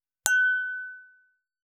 294イタリアン,バル,フレンチ,夜景の見えるレストラン,チーン,カラン,キン,コーン,チリリン,カチン,チャリーン,クラン,カチャン,クリン,シャリン,チキン,コチン,カチコチ,チリチリ,シャキン,カランコロン,パリーン,ポリン,トリン,
コップ効果音厨房/台所/レストラン/kitchen食器
コップ